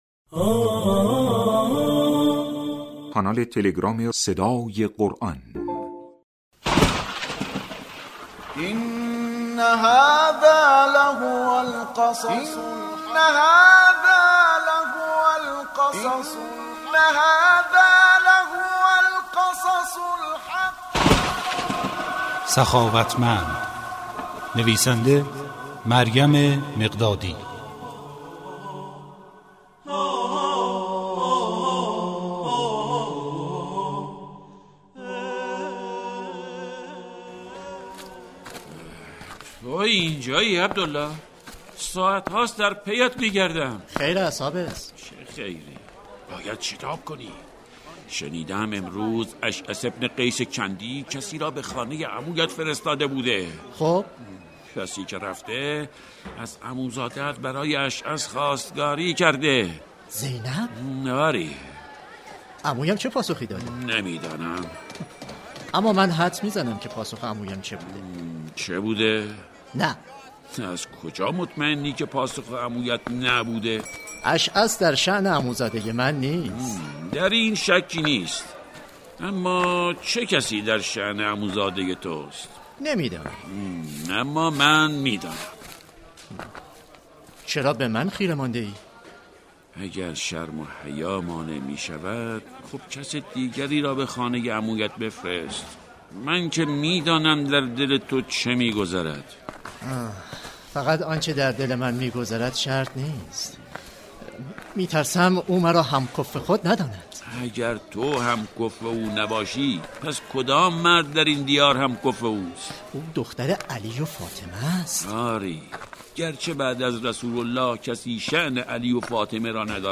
نمایش کوتاه رادیویی"سخاوتمند" روایت شرطی است که حضرت زینب(س) برای خواستگار خویش تعیین می کند ...